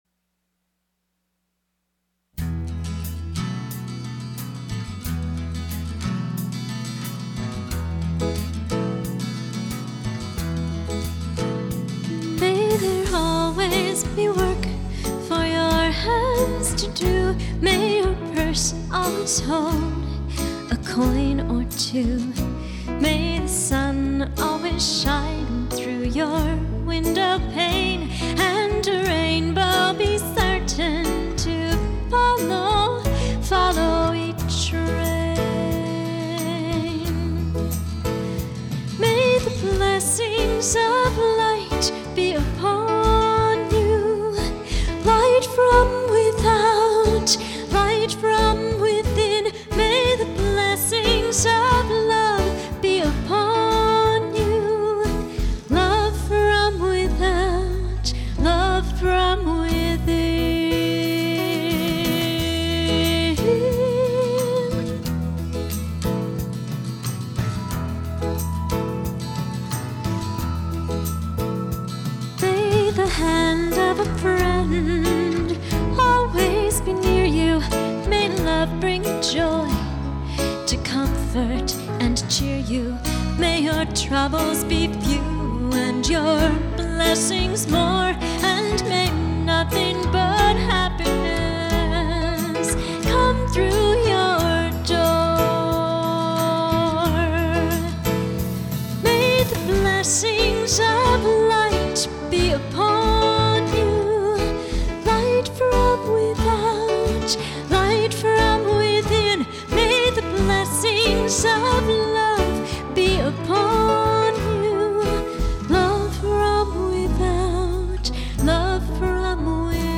STYLE:  Piano Propelled FOLK/POP,
Contemporary and Neo-traditional Folk